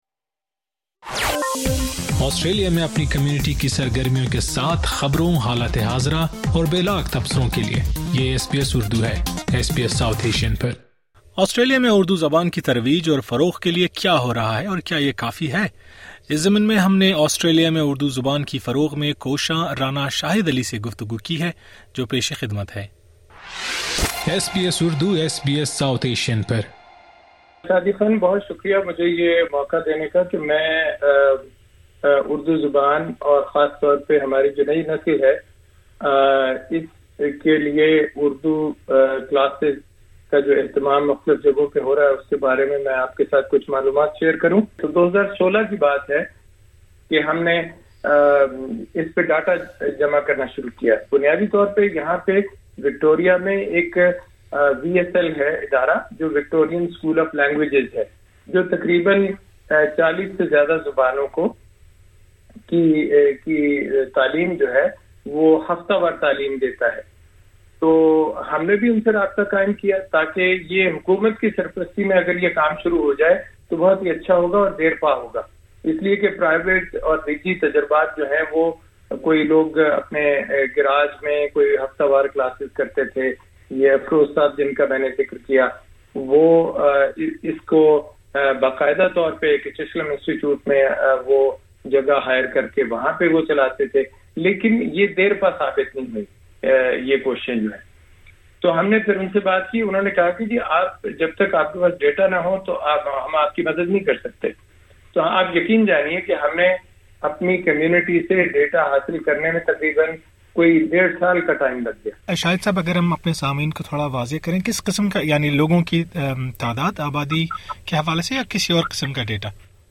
گفتگو کی جو پیش خدمت ہے